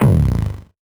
Index of /musicradar/8-bit-bonanza-samples/VocoBit Hits
CS_VocoBitB_Hit-18.wav